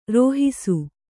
♪ rōhisu